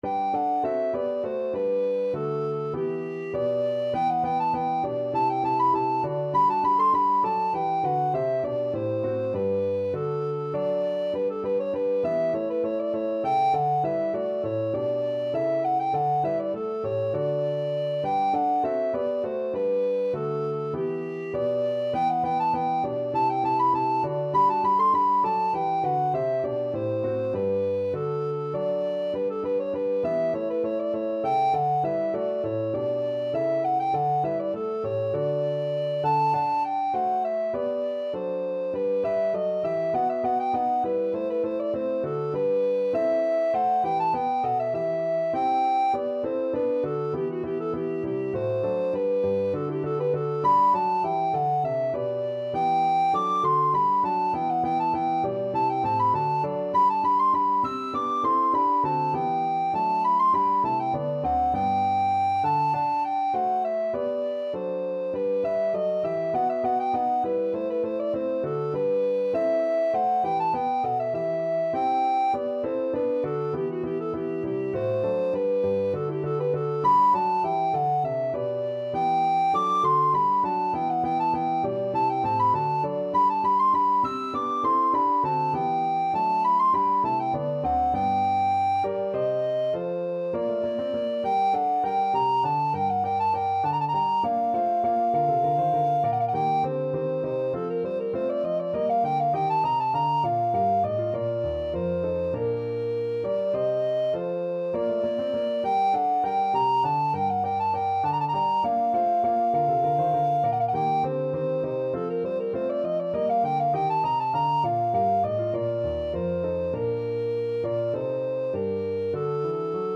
Alto Recorder
G major (Sounding Pitch) (View more G major Music for Alto Recorder )
4/4 (View more 4/4 Music)
Classical (View more Classical Alto Recorder Music)